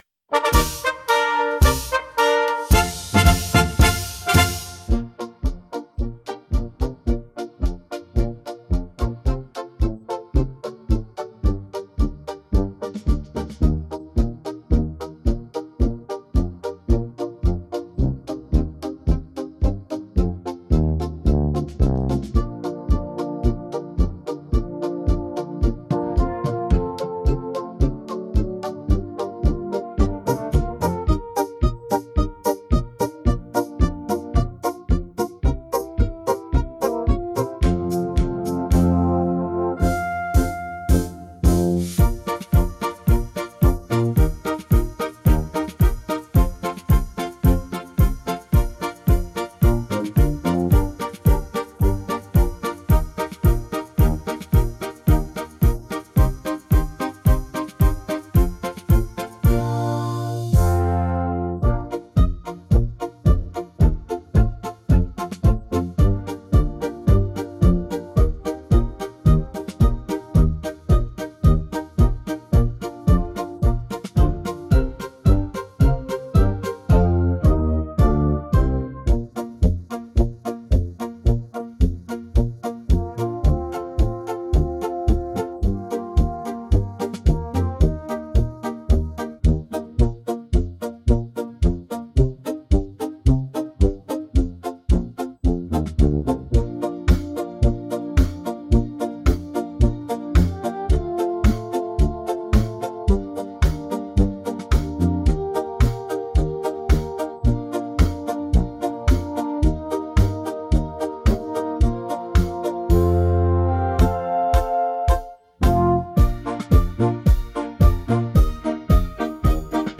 >>> die Melodie fürs Karaoke … und der Download.